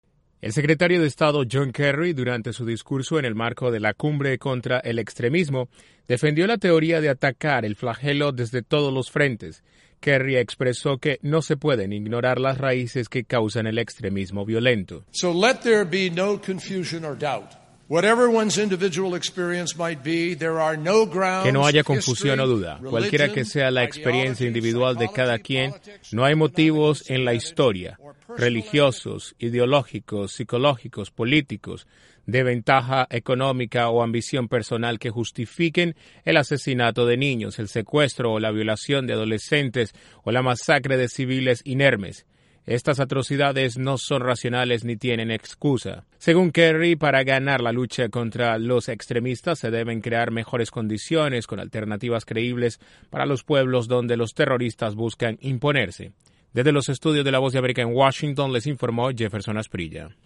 El secretario de Estado John Kerry resaltó durante la Cumbre contra el extremismo violento la importancia de atacar desde todos los frentes al extremismo. Desde la Voz de América en Washington informa